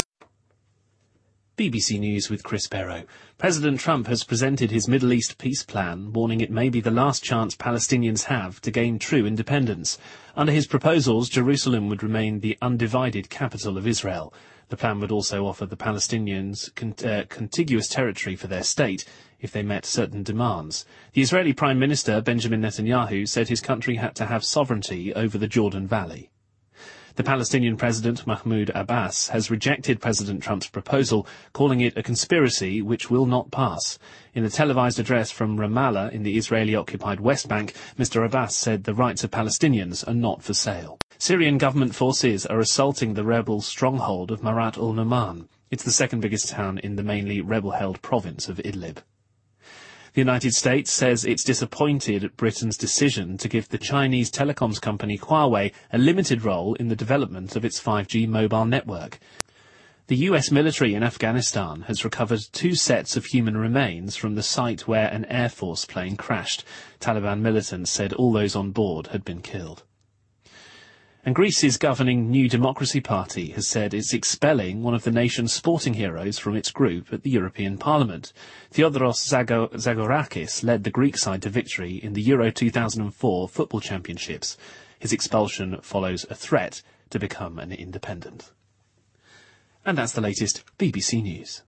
英音听力讲解:英国政府决定让华为“有限”参与5G建设